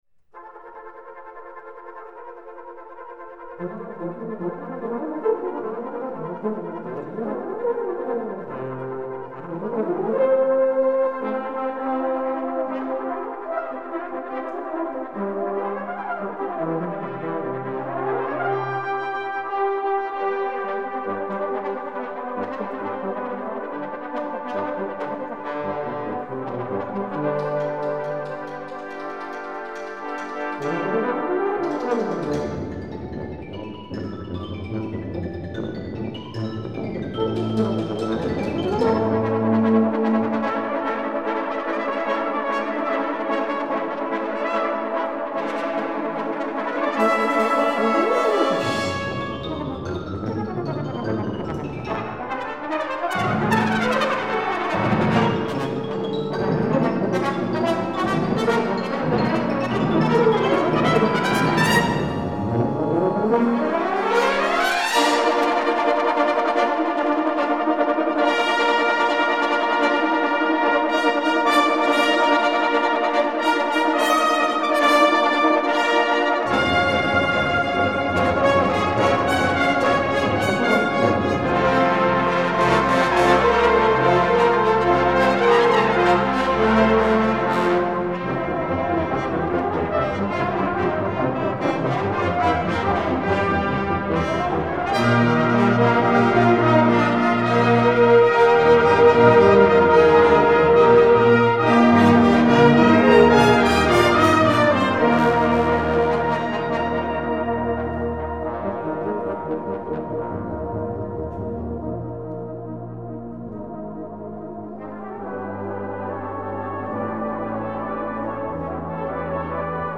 ブラスバンド
フルバンド
ソロ楽器なし